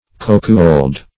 cokewold - definition of cokewold - synonyms, pronunciation, spelling from Free Dictionary Search Result for " cokewold" : The Collaborative International Dictionary of English v.0.48: Cokewold \Coke"wold\, n. Cuckold.